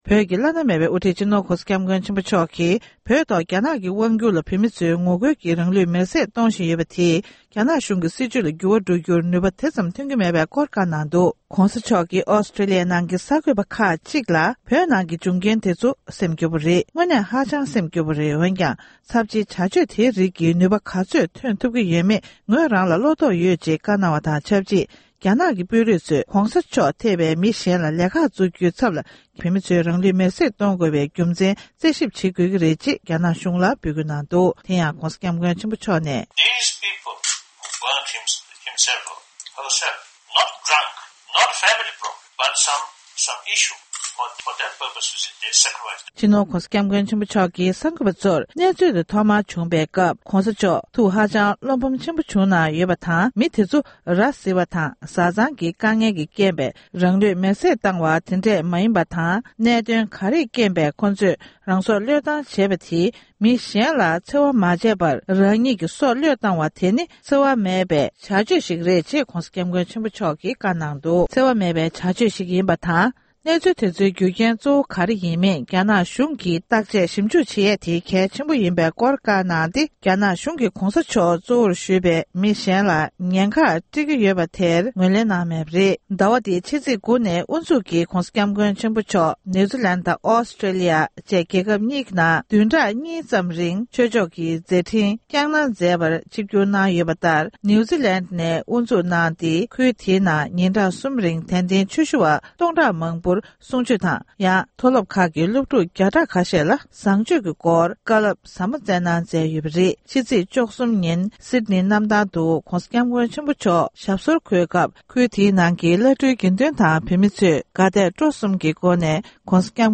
སྒྲ་ལྡན་གསར་འགྱུར། སྒྲ་ཕབ་ལེན།
གསར་འགྱུར་དཔྱད་གཏམ།